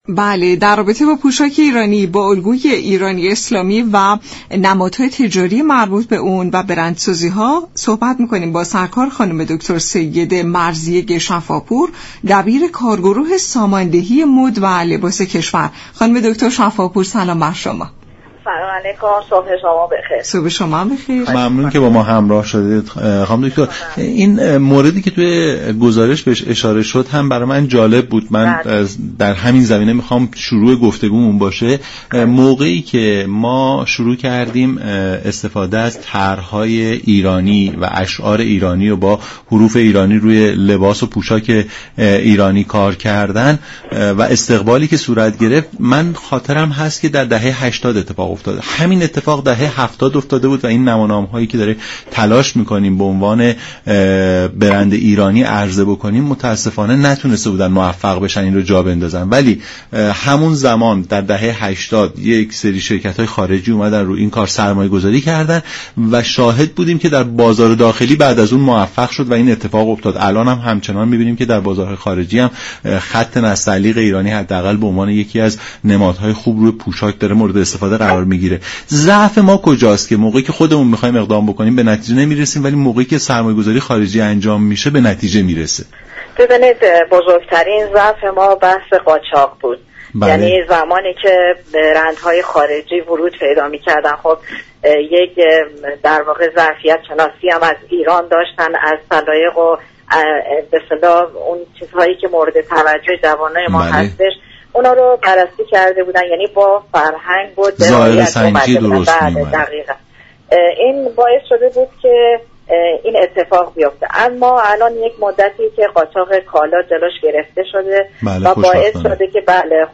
دبیركارگروه ساماندهی مد و لباس كشور در گفت و گو با رادیو ایران گفت: امسال در جشنواره بین‌المللی مد و لباس فجر، اتحادیه پوشاك در كنار طراحان قرار دارد.